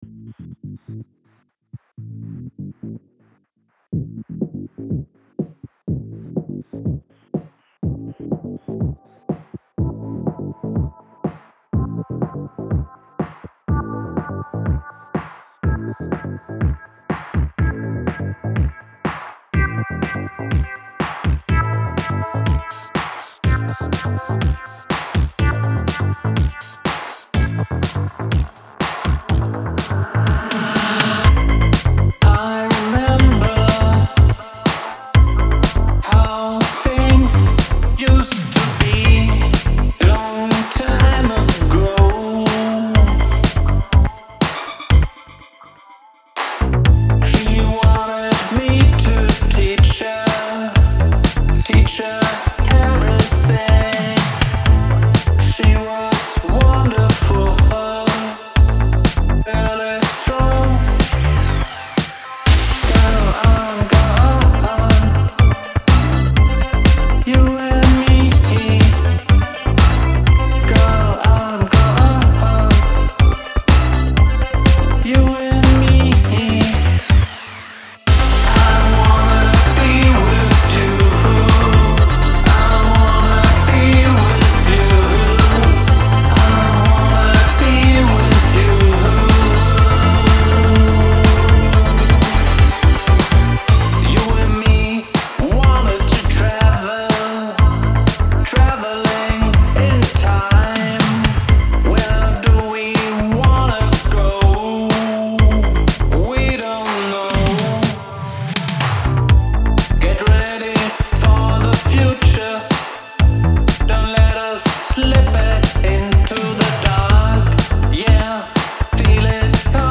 Traveling In Time | Pop | Work In Progress
Den Bass harmonisch an die Synth-Akkorde anzupassen geht schon, aber dadurch kriegt...